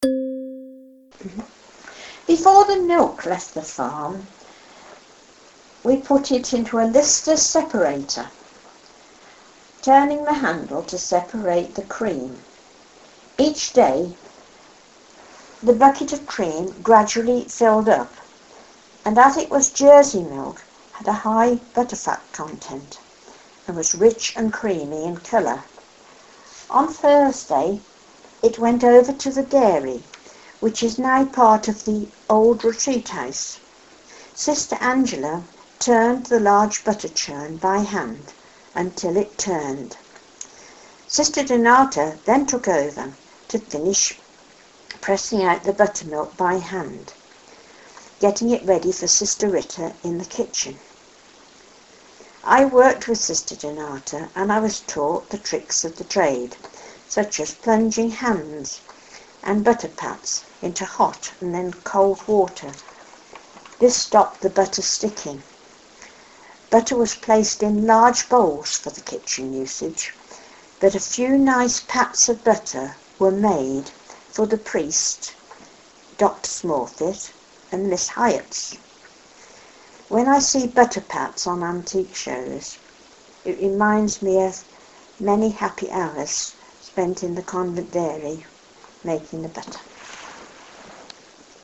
Oral History
The result of this work is a unique collection of recordings captured digitally and transcribed into document form so that they are available for both listening to and reading.